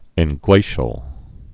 (ĕn-glāshəl)